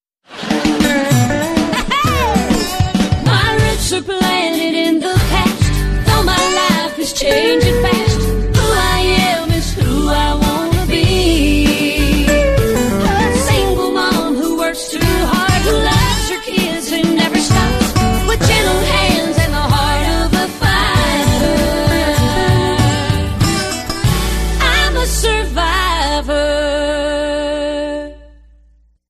Tags: TV Songs actors theme song show